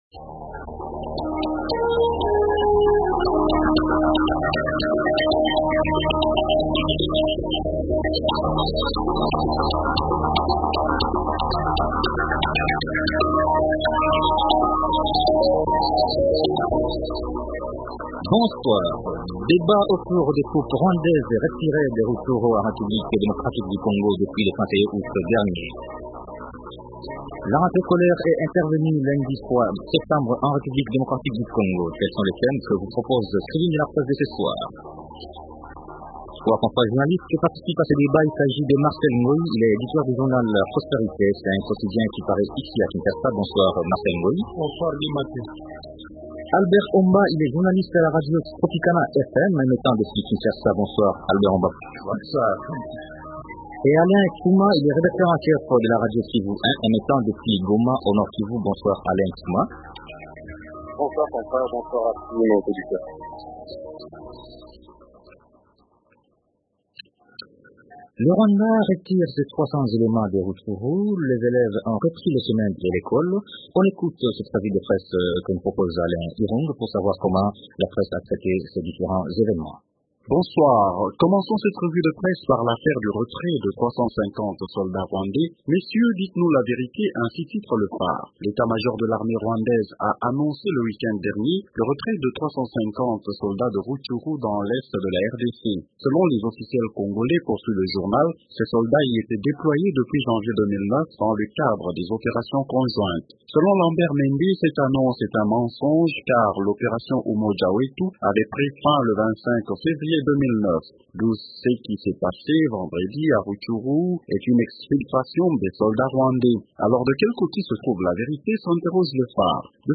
-Débat autour des troupes rwandaises retirées de Rutshuru en Rdc vendredi 31 Aout dernier ;